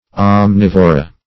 Search Result for " omnivora" : The Collaborative International Dictionary of English v.0.48: Omnivora \Om*niv"o*ra\, n. pl.